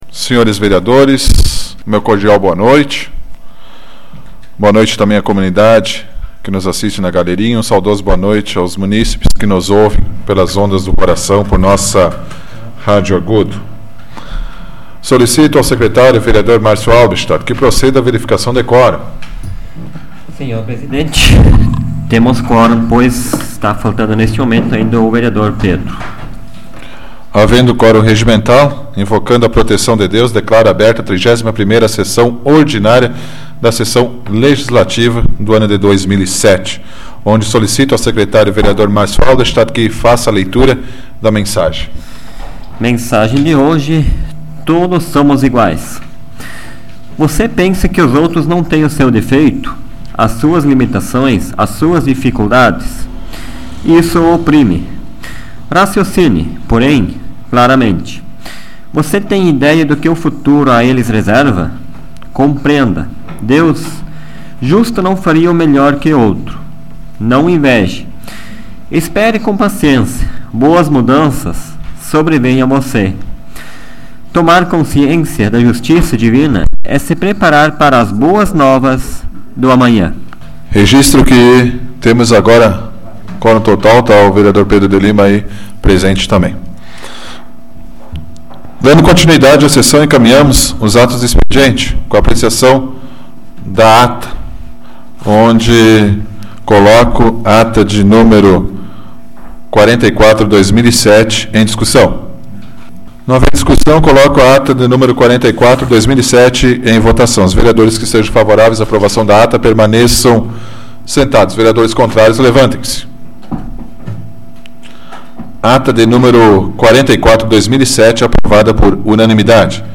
Áudio da 105ª Sessão Plenária Ordinária da 12ª Legislatura, de 05 de novembro de 2007